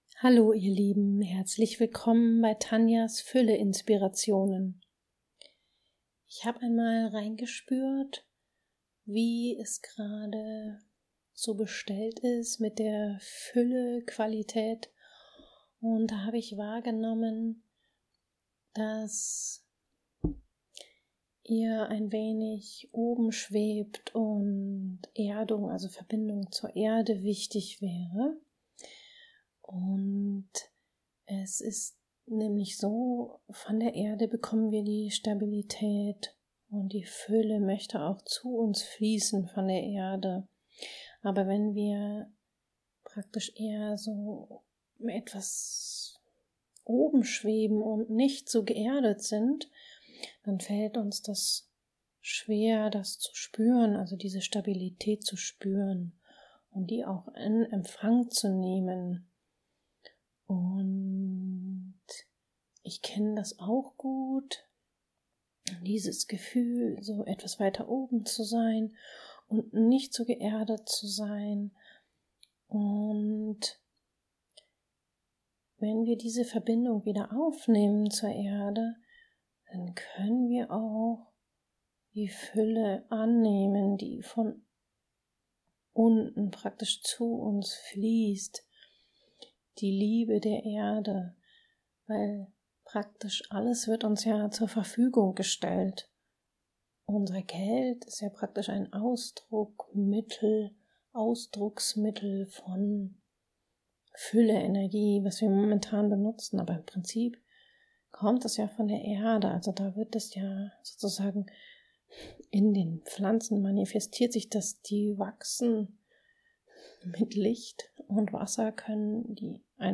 Meditation Verbindung zur Erde und deiner Fülle